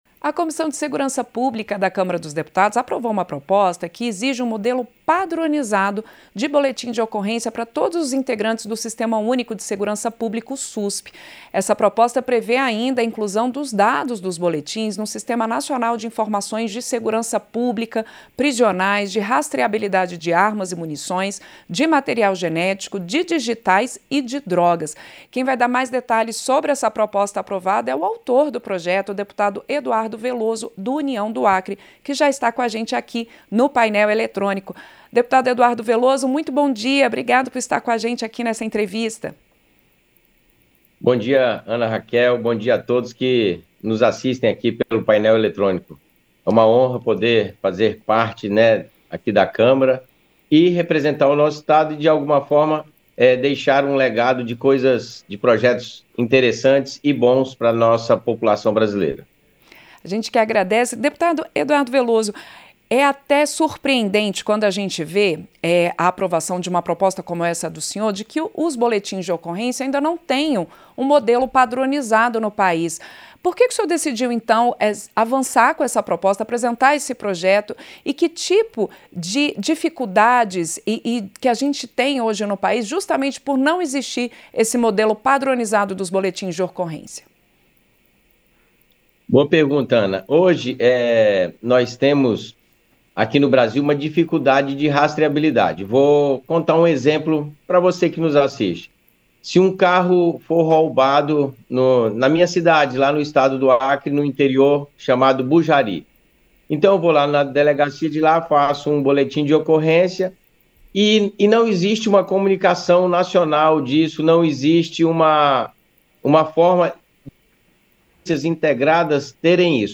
Entrevista – Dep. Eduardo Velloso (União-AC)